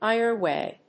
アクセントèither wáy